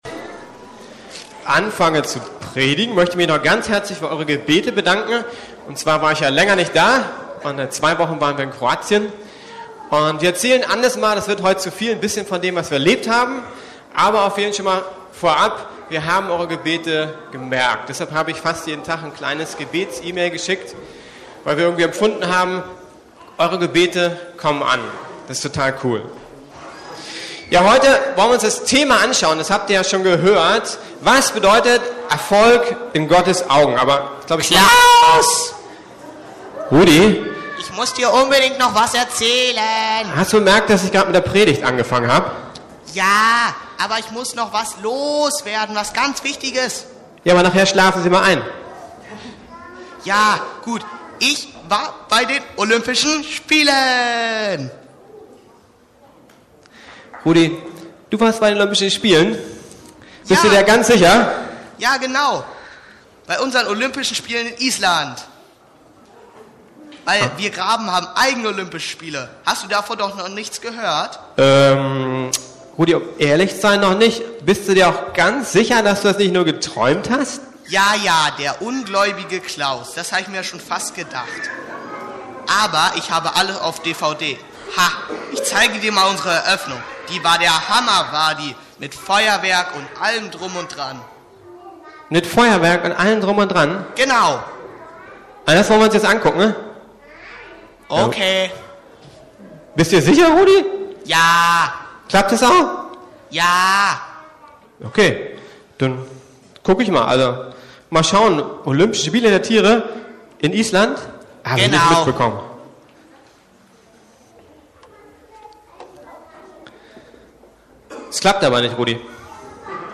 Was ist in Gottes Augen Erfolg? ~ Predigten der LUKAS GEMEINDE Podcast